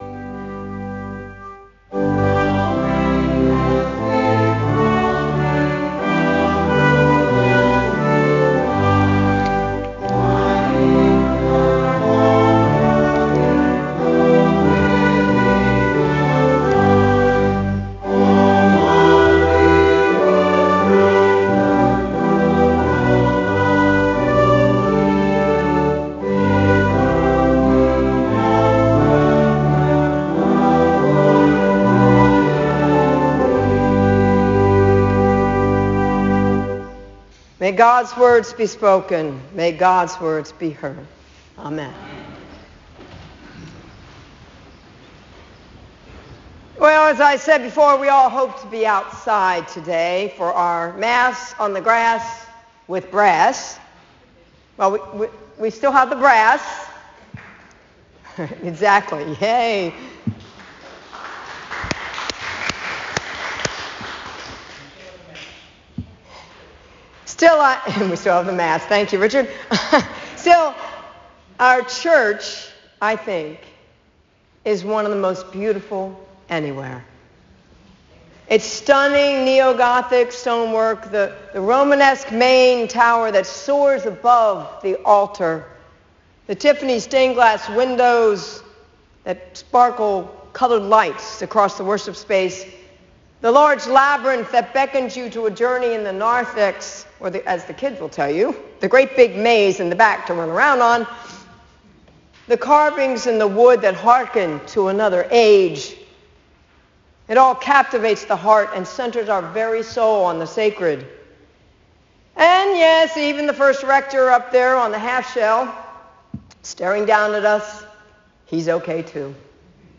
Trinity Sunday – June 12, 2022: May God’s words be spoken, may God’s words be heard.